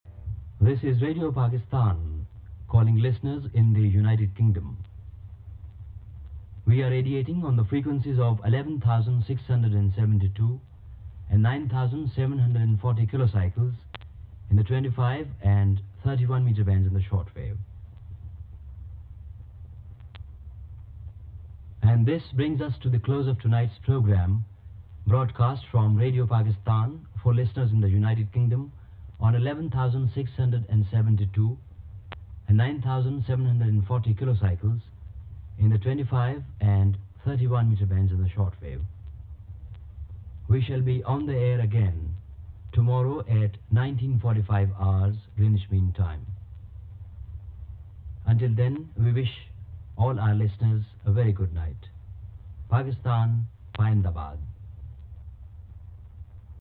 Station ID Audio